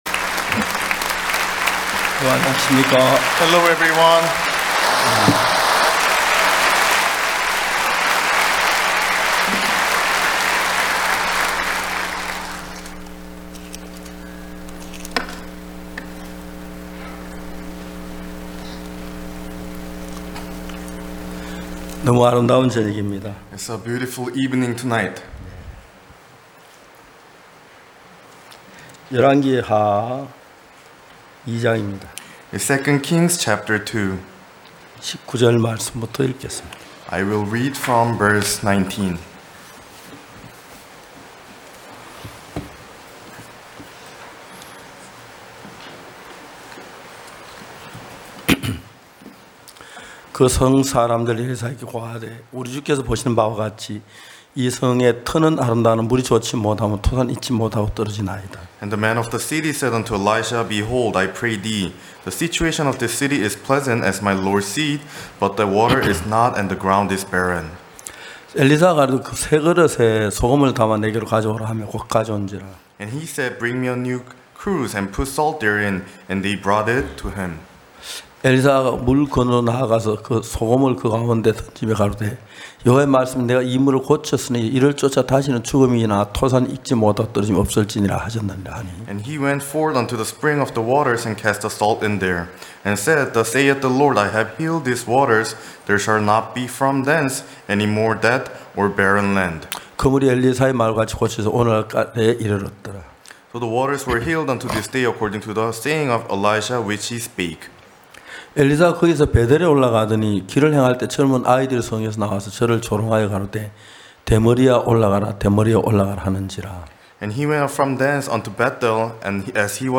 전국 각 지역의 성도들이 모여 함께 말씀을 듣고 교제를 나누는 연합예배.